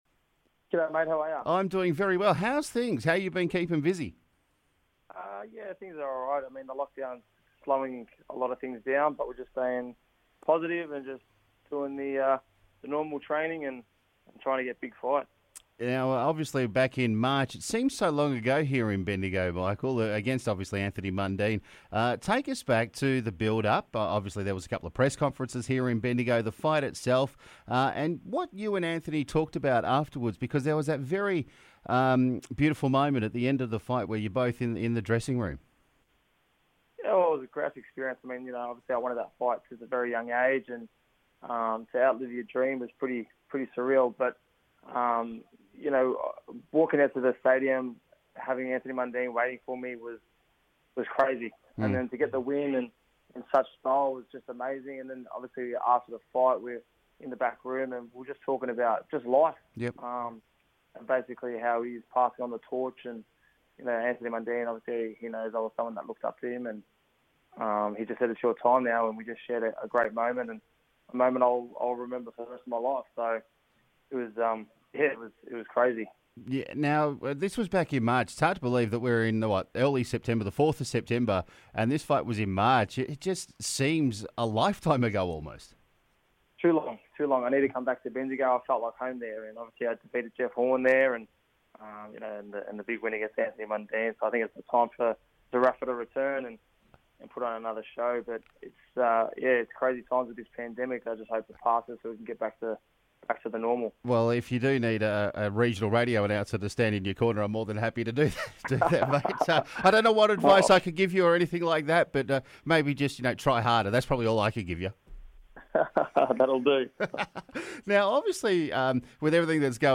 Boxer Michael Zerafa defeated Anthony Mundine in Bendigo earlier this year, Michael joined The Sideline View for a chat about life in lockdown, the fight in Bendigo and if he will come back.